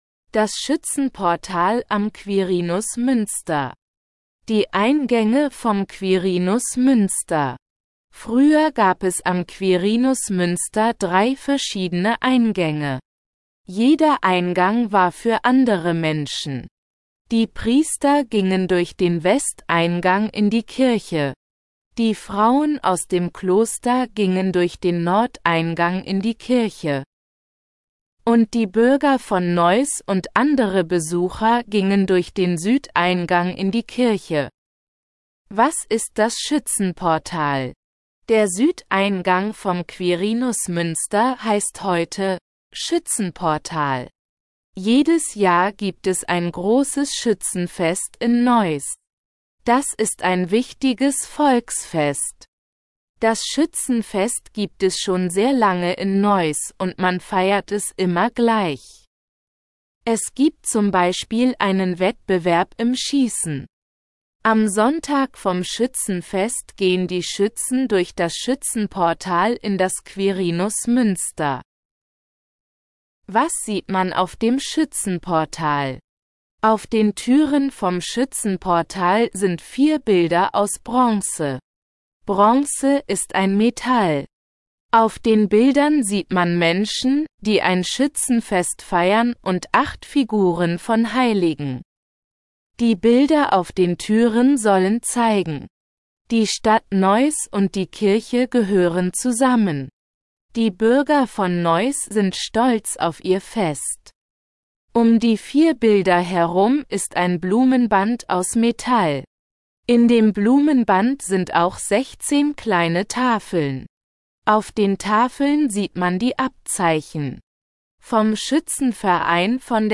Audio Guide Deutsch (Leichte Sprache)